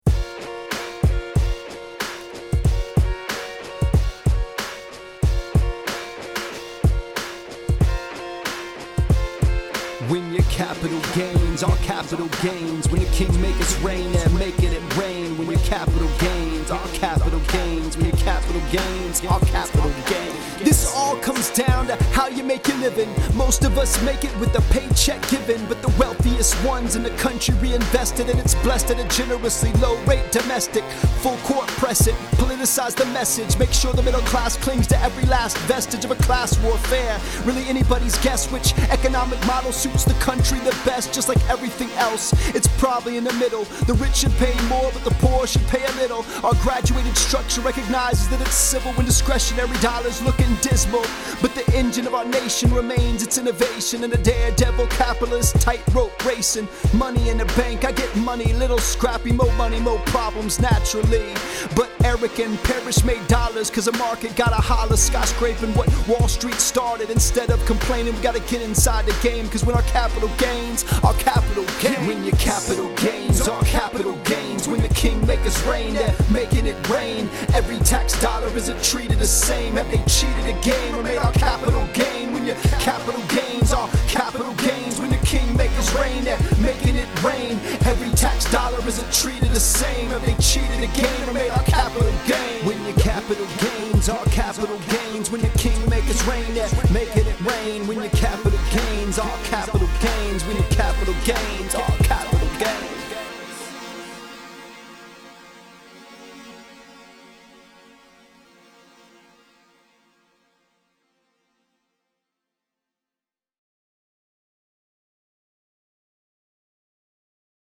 I’m not even the dopest economics-conversant rapper, unfortunately.